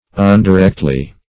undirectly - definition of undirectly - synonyms, pronunciation, spelling from Free Dictionary Search Result for " undirectly" : The Collaborative International Dictionary of English v.0.48: Undirectly \Un`di*rect"ly\, adv.